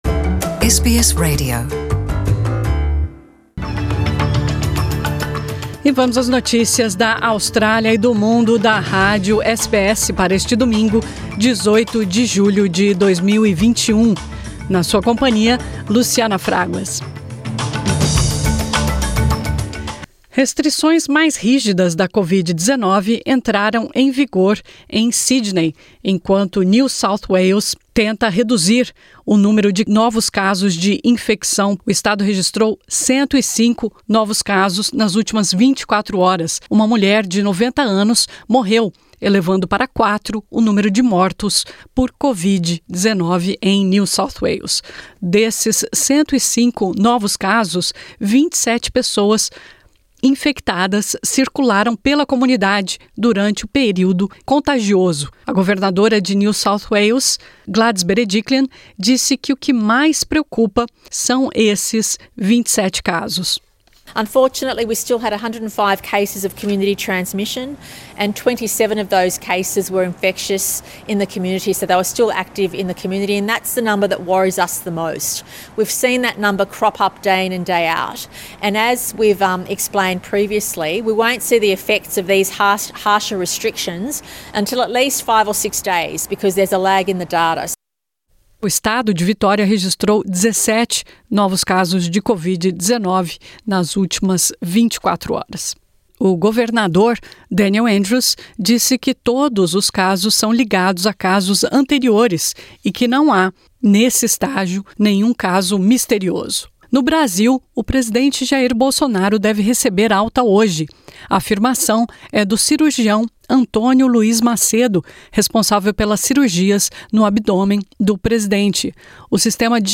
Notícias da Austrália e do Mundo | 18 de julho de 2021 | SBS Portuguese